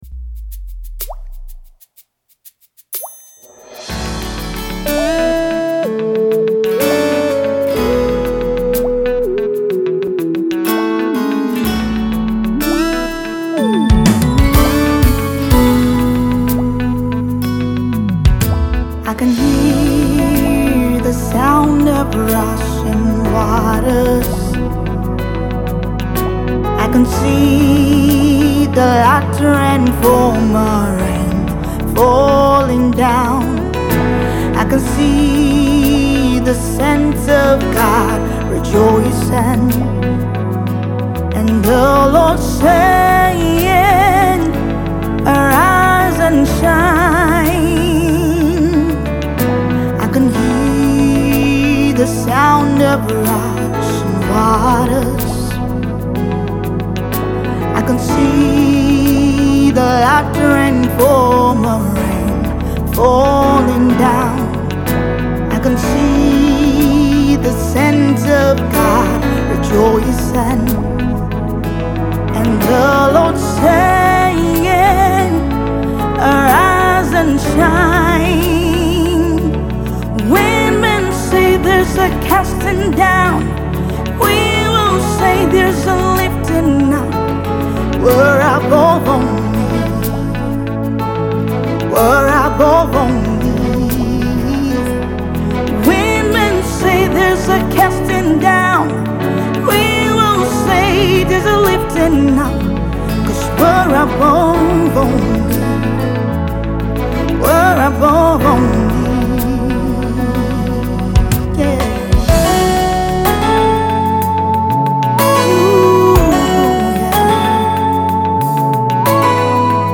gospel minister